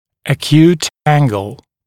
[ə’kjuːt ‘æŋgl][э’кйу:т ‘энгл]острый угол